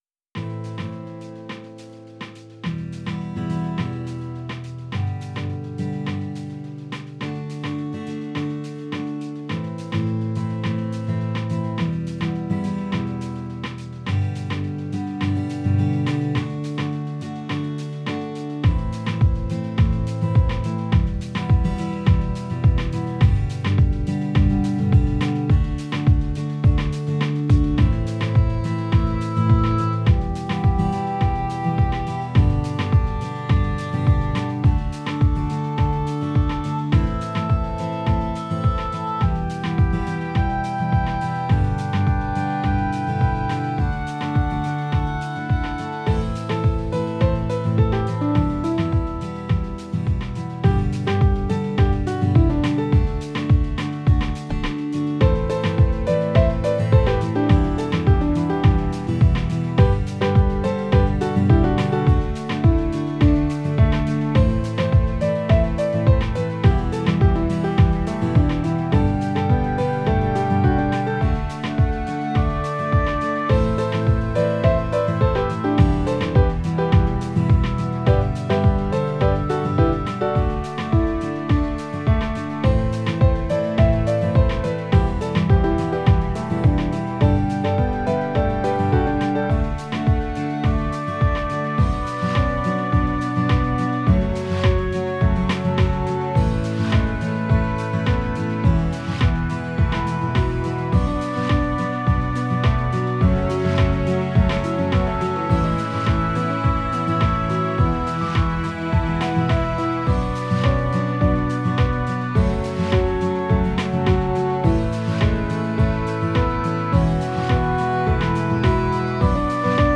Tags: Piano, Woodwinds, Strings, Guitar, Percussion
Title Cavort Opus # 568 Year 2023 Duration 00:03:42 Self-Rating 5 Description Apparently I'm in a guitar phase. mp3 download wav download Files: mp3 wav Tags: Piano, Woodwinds, Strings, Guitar, Percussion Plays: 102 Likes: 20